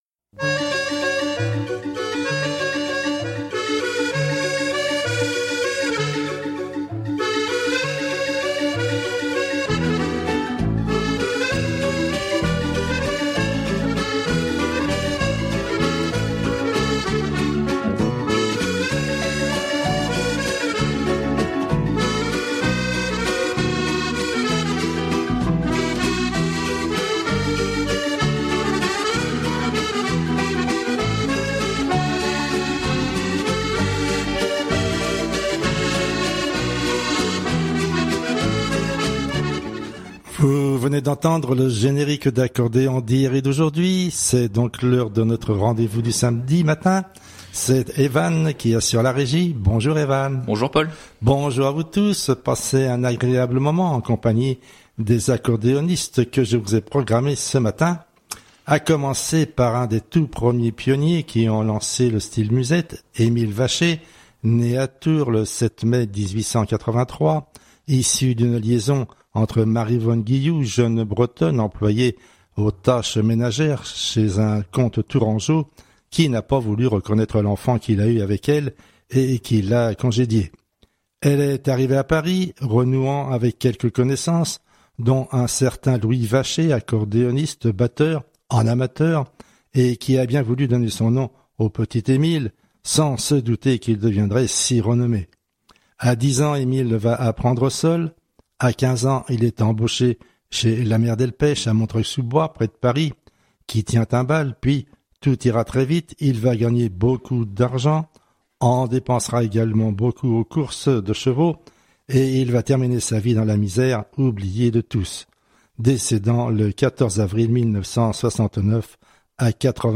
Amateurs d’accordéon bonjour